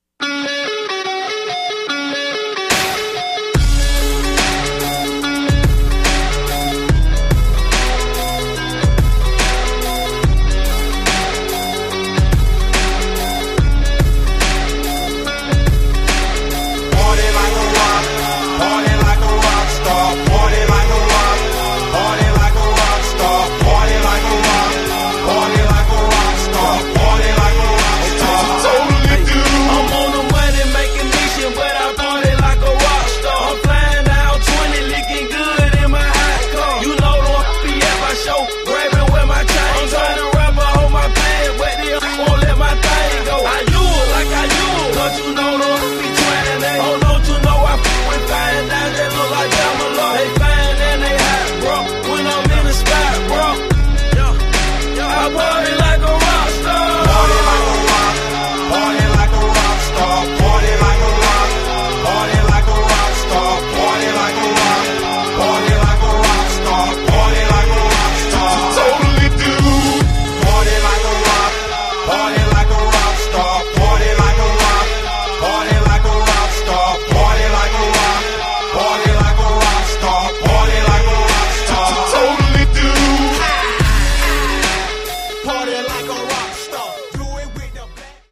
71 bpm
Clean Version